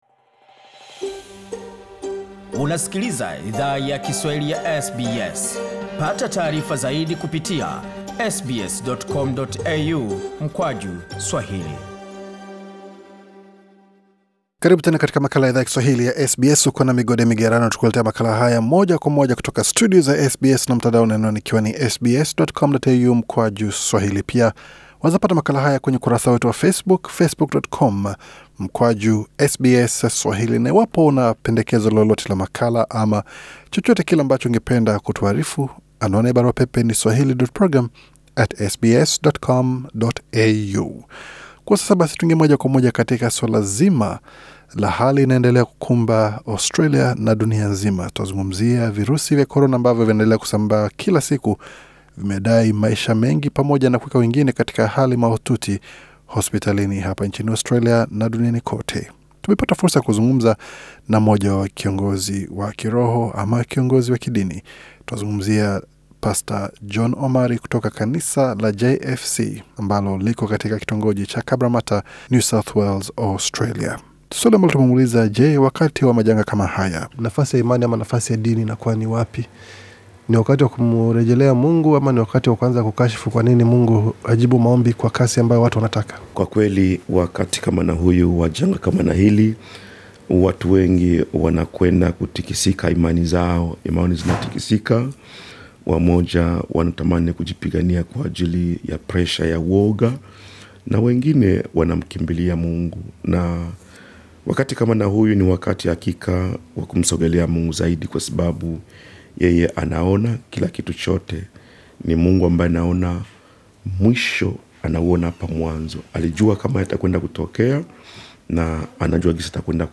Kwenye mahojiano haya, walifafanua jinsi Mungu hujibu maombi, pamoja na jinsi muumini anastahili kuwa panapotokea janga /changamoto yoyote.